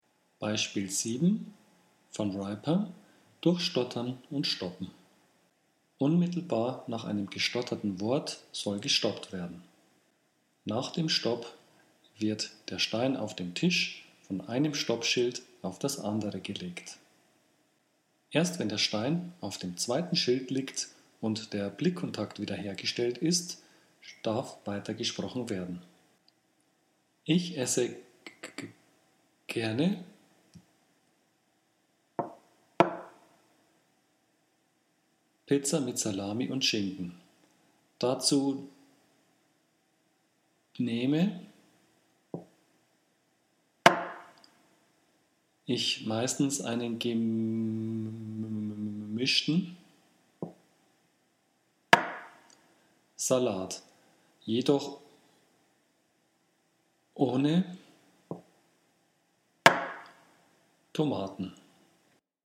Sprechtechniken in der Stottertherapie. Übersicht über gängige Sprechtechniken mit Audio-Beispielen
Soundbeispiel 7 (Van Riper: Durchstottern und Stoppen)
07_Van_Riper_Stoppen.mp3